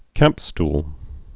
(kămpstl)